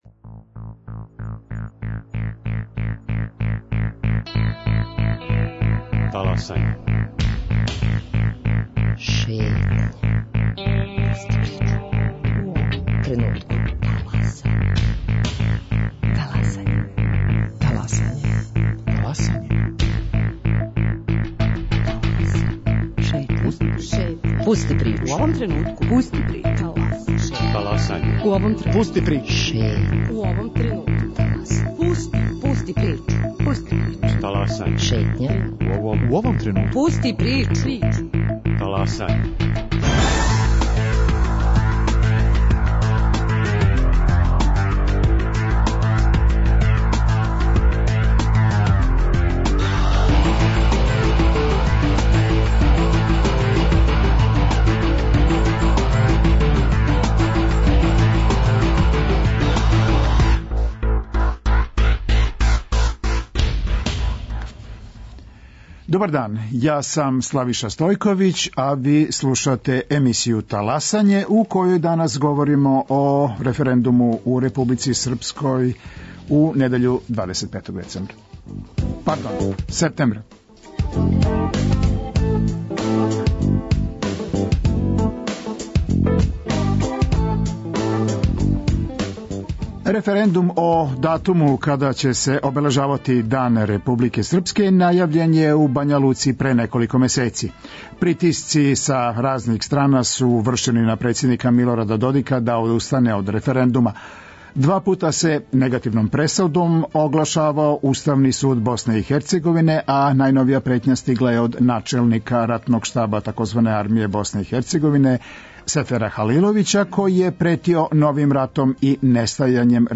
О томе са гостима у студију